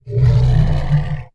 MONSTERS_CREATURES
ORCH_Breath_02_mono.wav